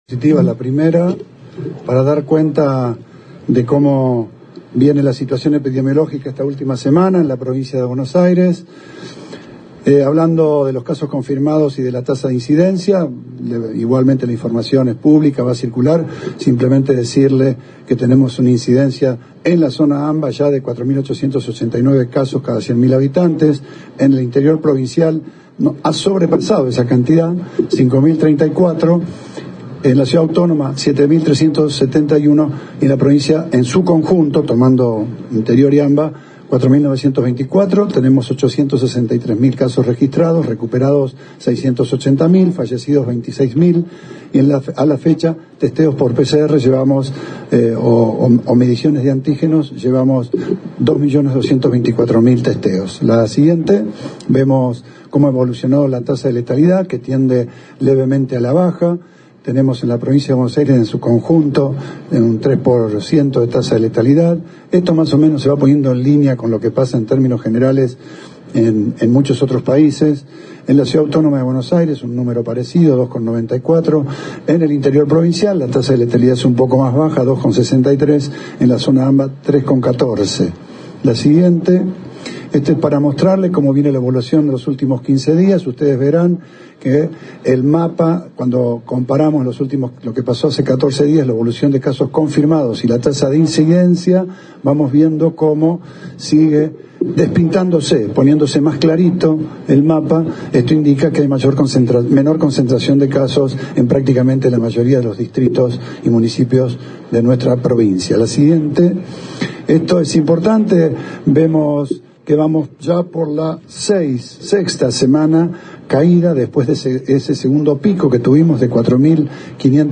Luego de reunirse en la Secretaría de Turismo con los mandatarios distritales y miembros de su gabinete, el Gobernador de la provincia de Buenos Aires ofreció una conferencia de prensa en un balneario local, acompañado por el intendente Arturo Rojas.